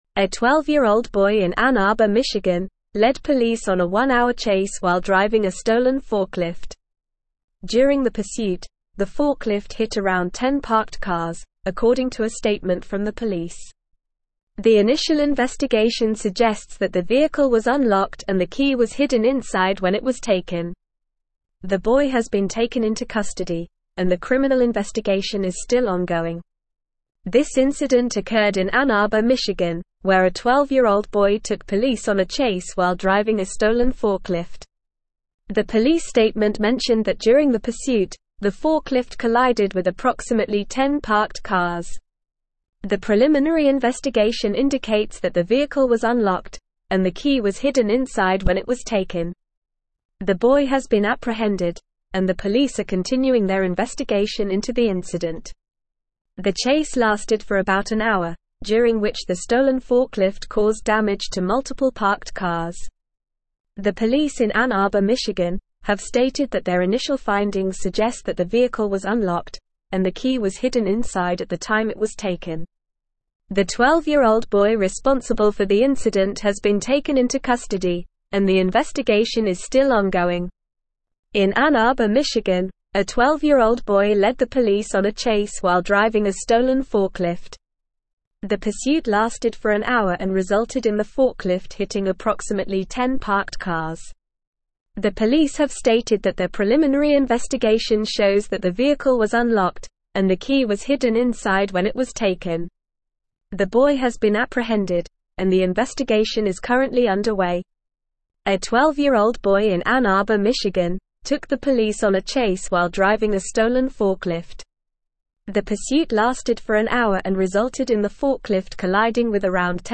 Normal
English-Newsroom-Advanced-NORMAL-Reading-12-Year-Old-Boy-Steals-Forklift-Leads-Police-on-Chase.mp3